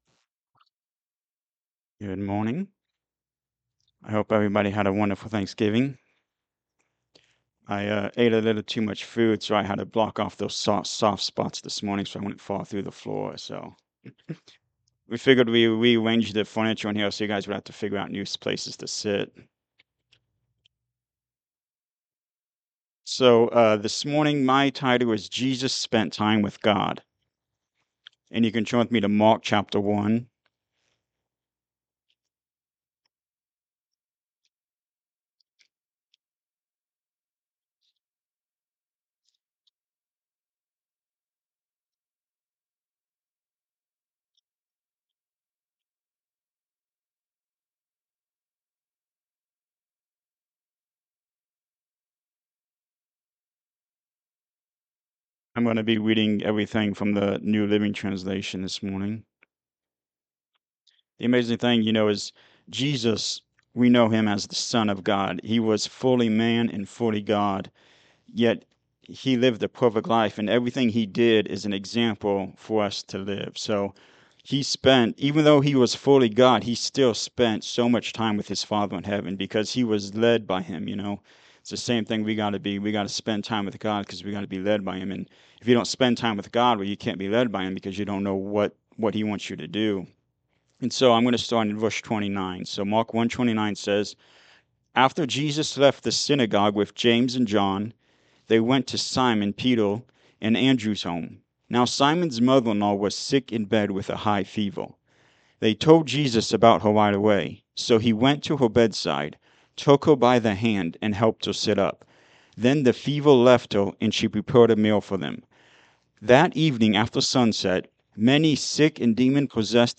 Mark 1:29-39 Service Type: Sunday Morning Service Ministry means serving others.